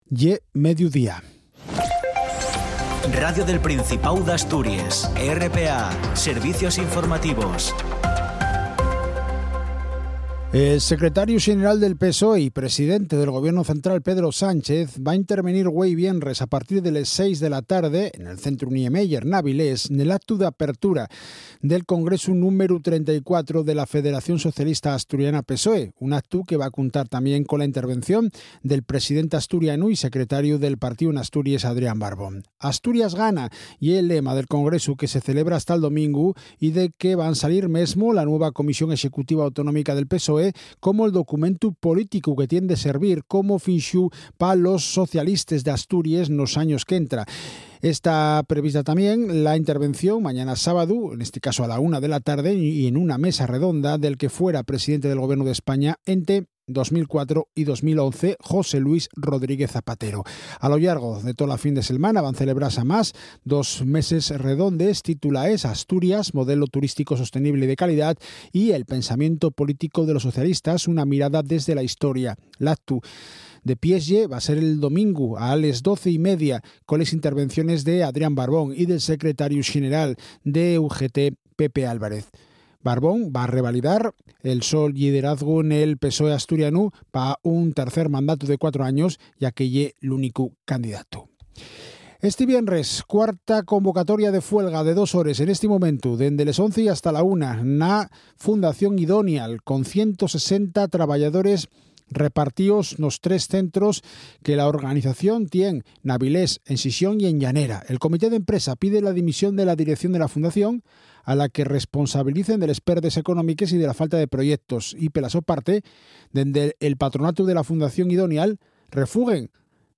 El boletín de las 12:00 horas tiene una duración de 10 minutos y se emite en asturiano. La actualidad general del día en nuestra lengua.